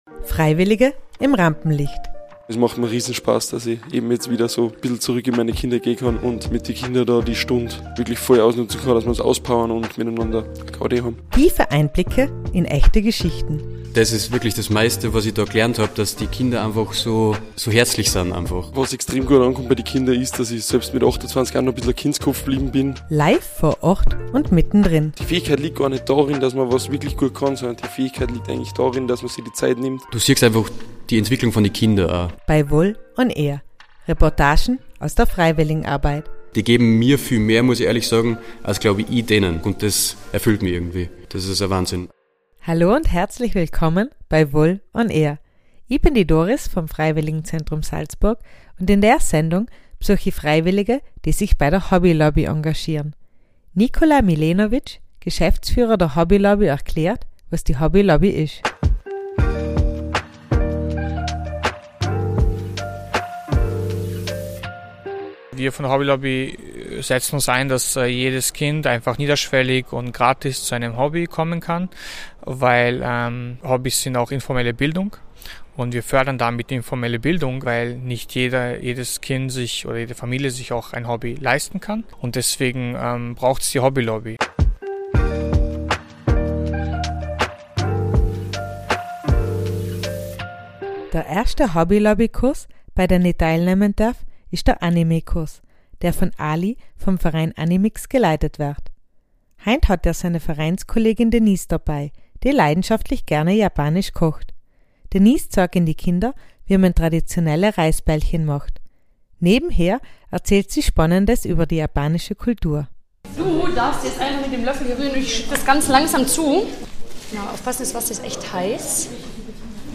#8 Freiwillige bei der Hobby Lobby ~ VOL ON AIR –- Reportagen aus der Freiwilligenarbeit Podcast